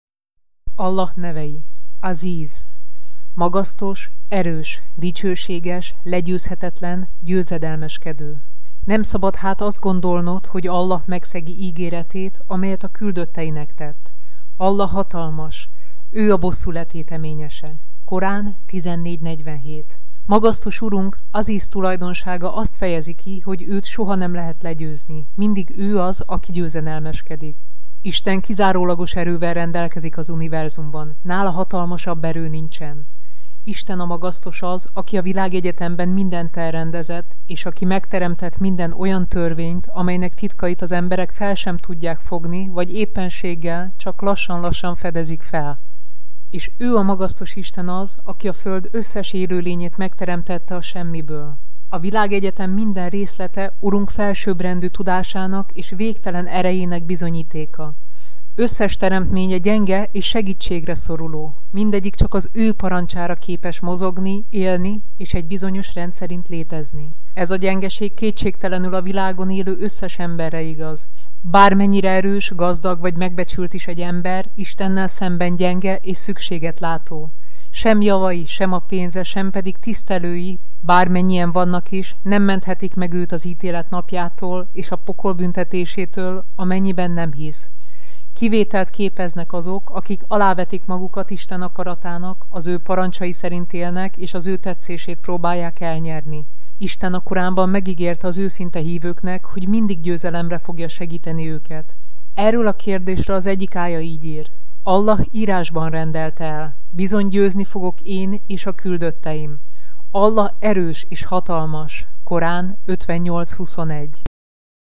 Allah nevei hangoskönyv